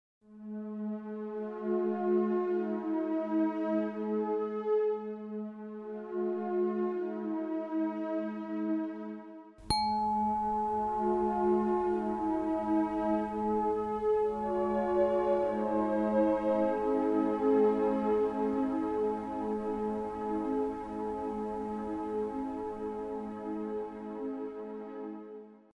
Sie hören jeweils eine begleitende auf die jeweilige Frequenz abgestimmte Tonfolge und die Klangröhre, die exakt in der entsprechenden Frequenz schwingt.
852 Hz (Klang, Reaktion) - LAbii reatumKomposition "Spirituelle Heimat"
852 Hz I.mp3